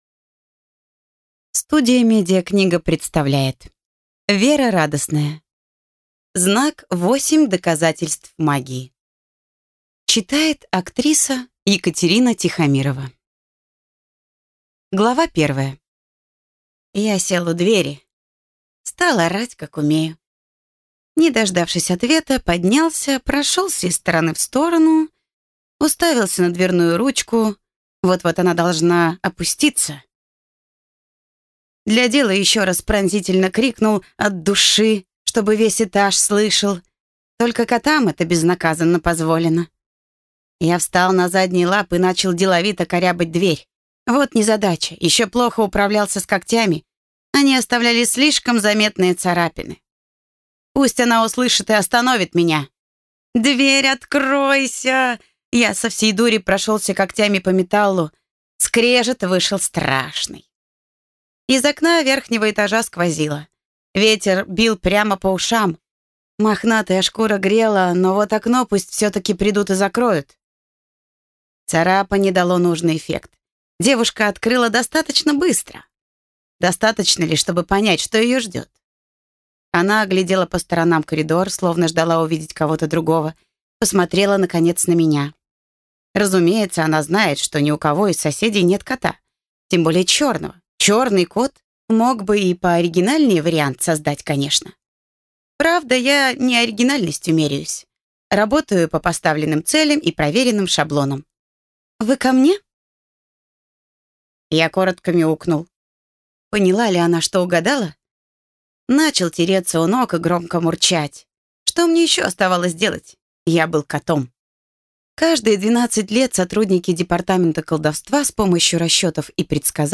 Аудиокнига Знак. Восемь доказательств магии | Библиотека аудиокниг